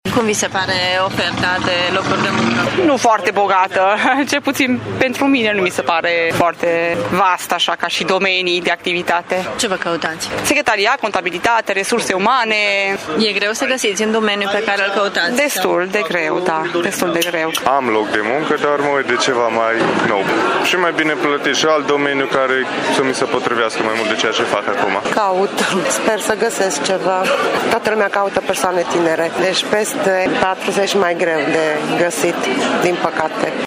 Deși ofertele sunt multe, târgumumureșenii au dificultăți să-și găsească un loc de muncă: